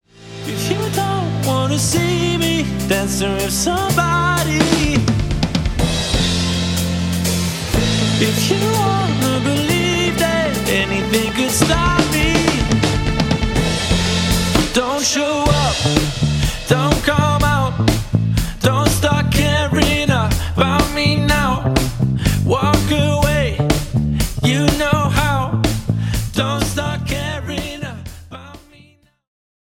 • Modern function band with 2 x male vocalists